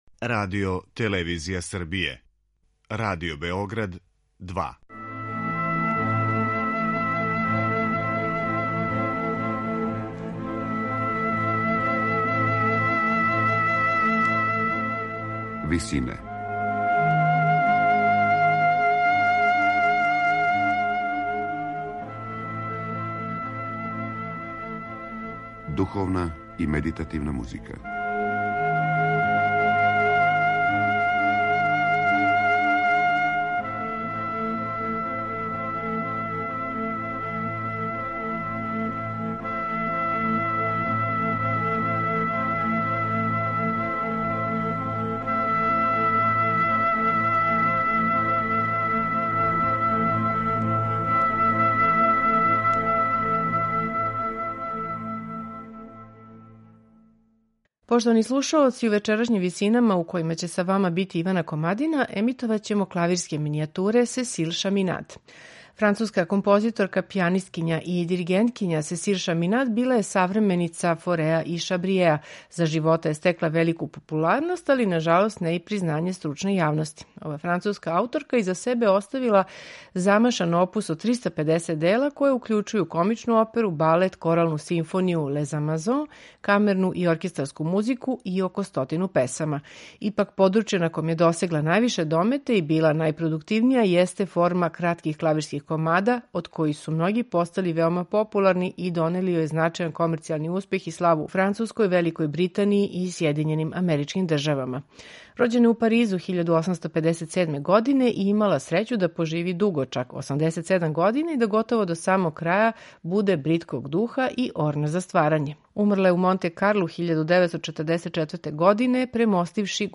Клавирска музика